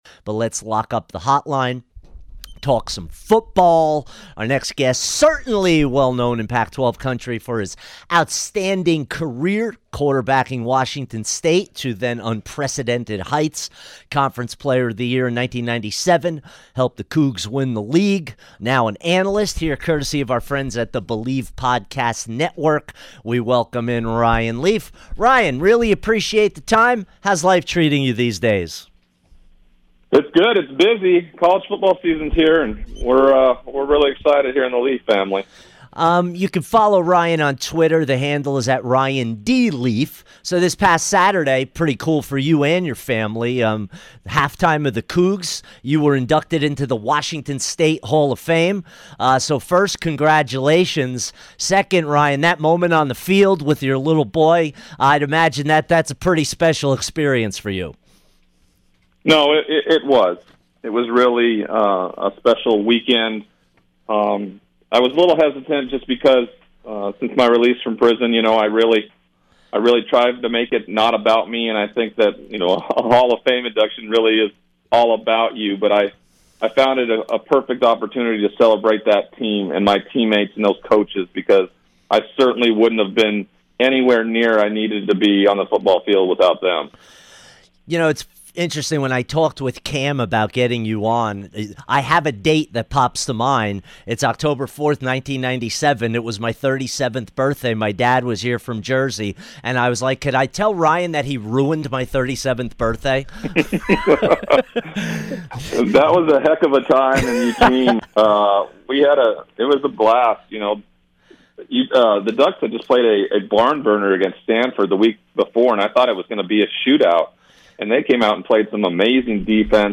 Sports Talk - Ryan Leaf Interview 9-10-19